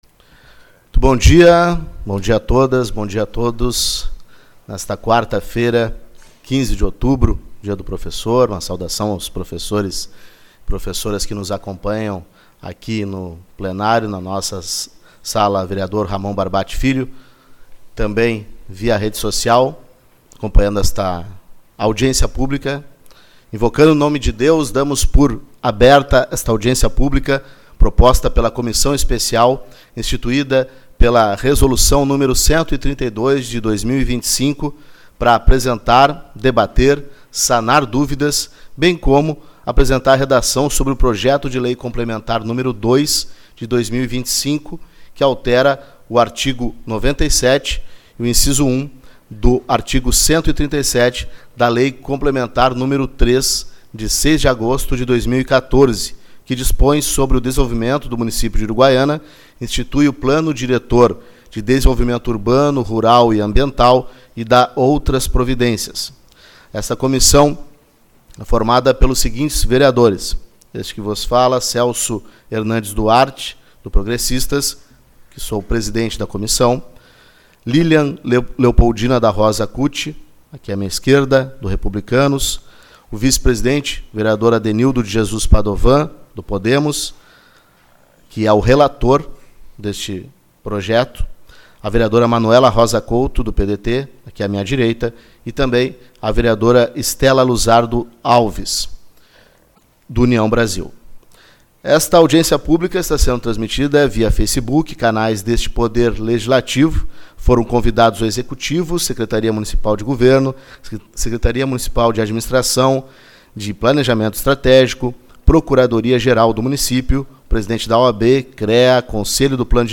15/10 - Audiência Pública-PL que altera o Plano Diretor